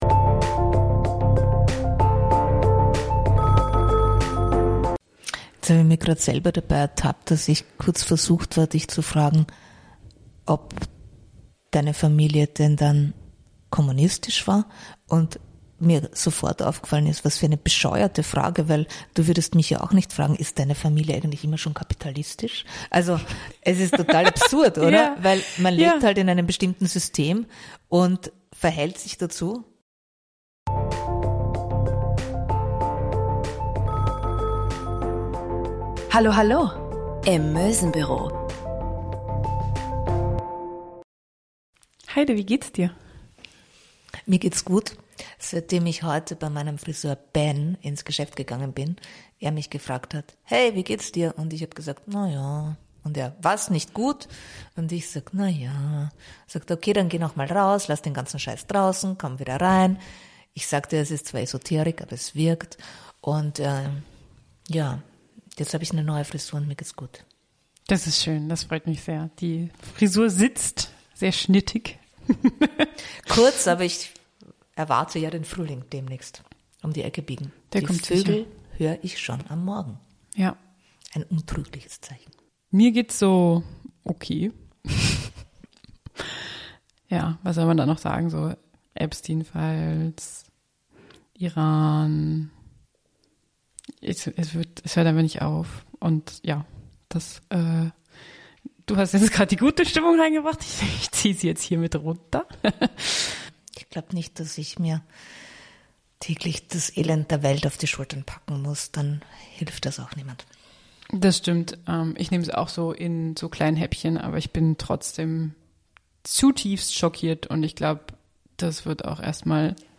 Wir schneiden unsere Folgen mit viel Herzblut selbst. Da wir noch im "Trial and Error"-Modus sind, empfehlen wir für den vollen Genuss diese Folge über Lautsprecher oder mit zwei Kopfhörern zu hören.